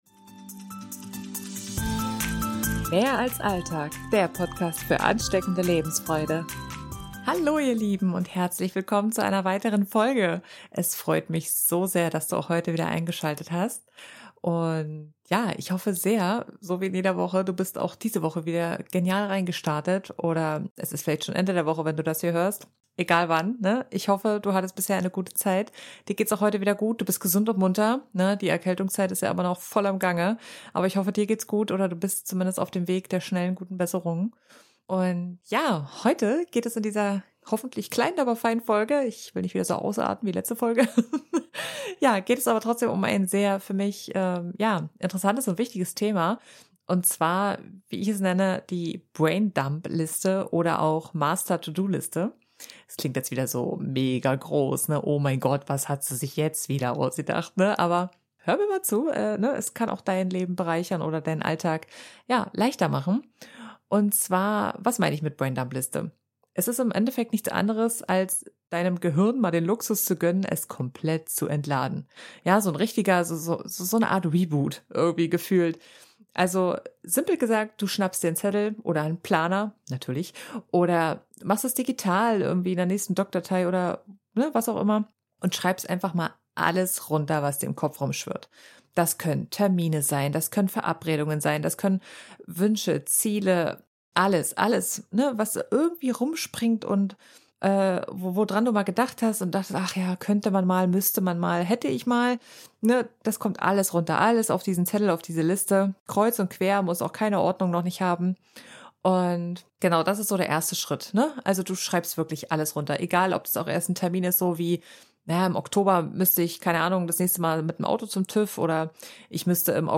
Beschreibung vor 1 Jahr Entdecke in der heutigen Folge eines der effektivsten Tools, um deinen Kopf endlich freizubekommen: die Braindump-Liste! Ich verrate dir meine Herangehensweise und gebe dir viele Beispiele zur Liste mit an die Hand. Intro-/Outromusik des Podcasts